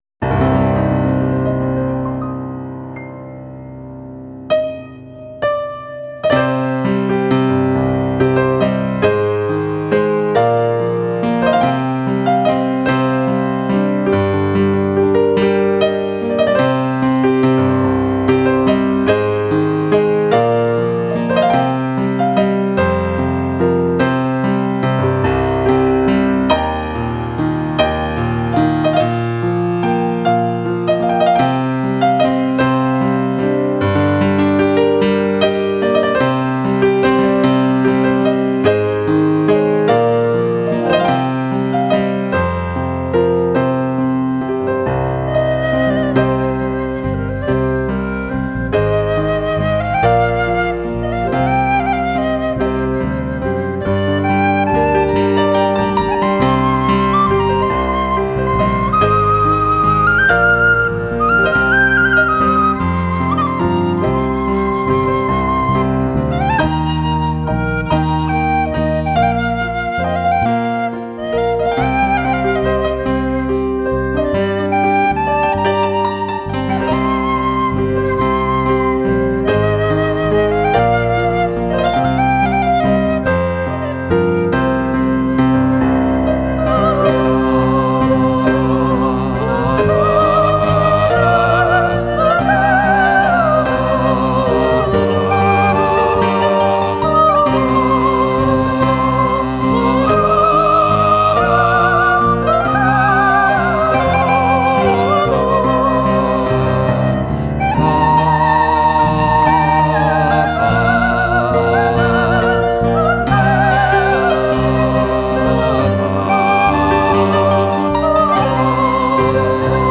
Biographical Information Spring Muse is a fusion of five experienced musicians and performers who came together in 2009 to form a new, full sound.
Inspired mainly by the Irish tradition but influenced by many other musical forces, Spring Muse has a unique sound that they use to tell stories and to create a wide variety of moods, to engage and entertain every audience.
The varied instrumentation and unusual arrangements make the sound itself exciting, and the songs always pull the listener in, through lively tunes and entertaining lyrics.